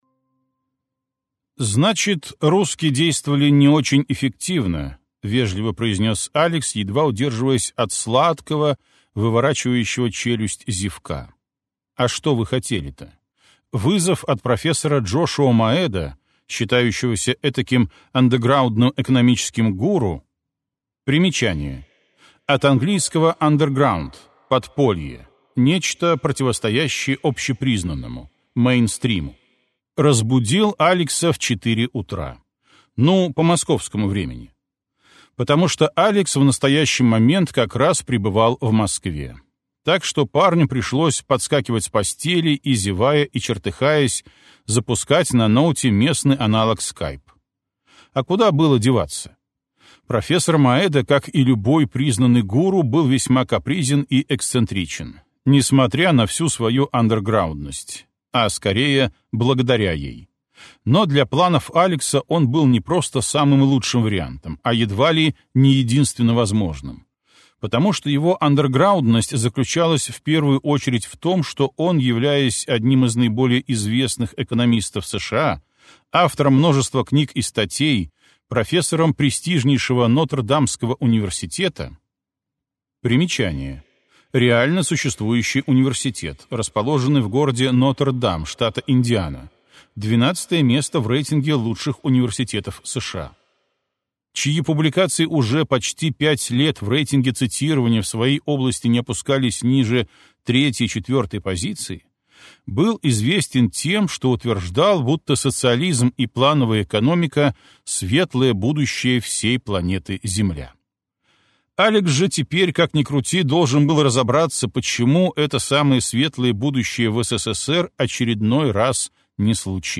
Аудиокнига Швейцарец. Возвращение - купить, скачать и слушать онлайн | КнигоПоиск